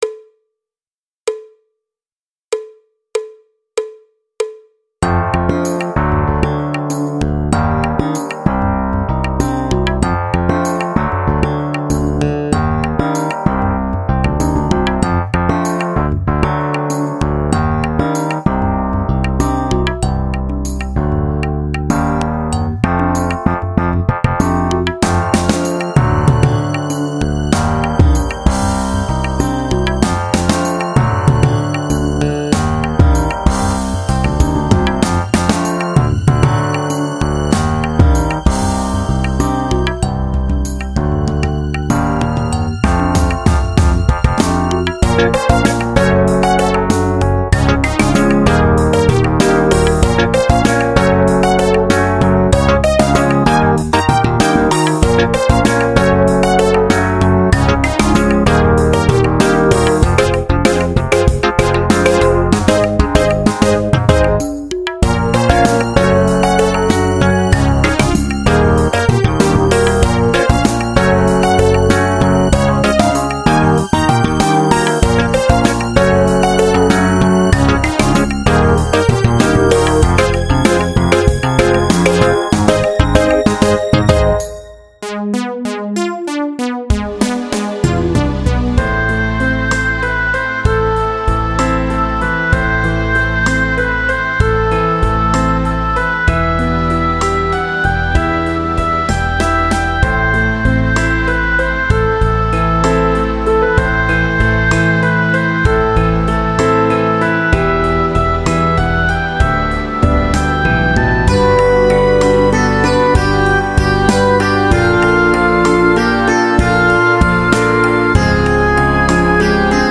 Batterie Solo